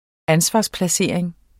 Udtale [ ˈansvɑs- ]